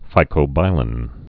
(fīkō-bīlĭn)